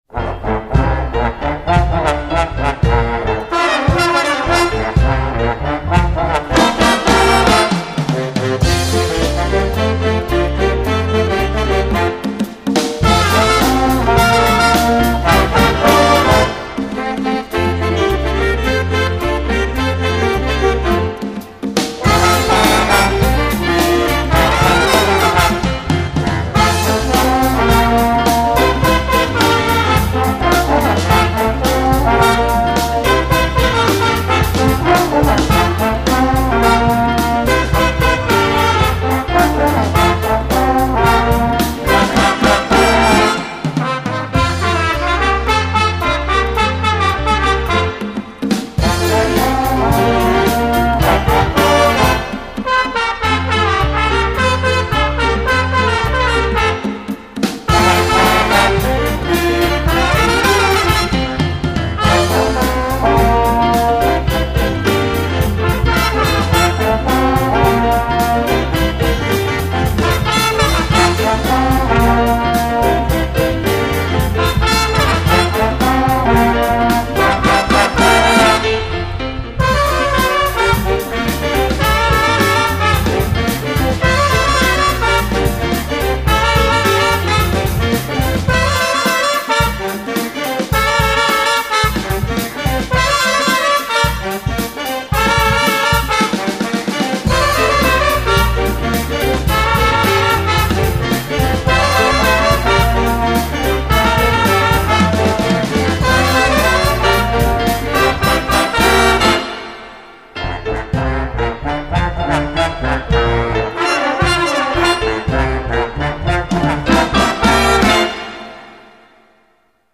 J A Z Z   B A N D S